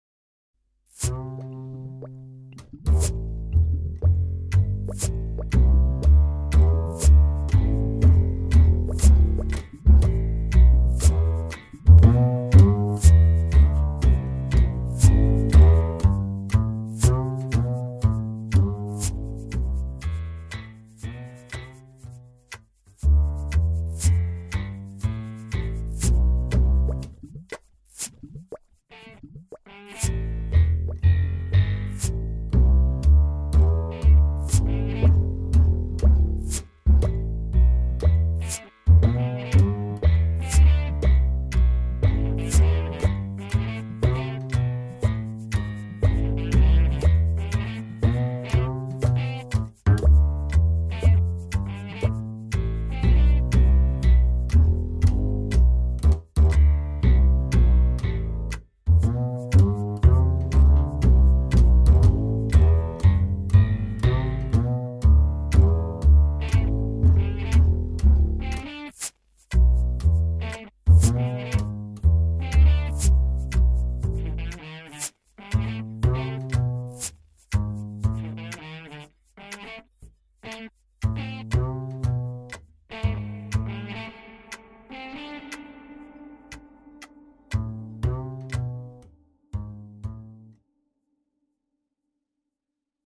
Nú er ég orðinn nokkuð sáttur við afraksturinn, búinn að lengja laglínuna upp í 1:33, á köflum tvöfalda bassalínuna, bæta við meira bobli og píanó og brassi.
Mér finnst þetta svolítið blúsaður taktur þannig að lagið hefur hlotið vinnsluheitið "Spilled beer" (eða bjórsull).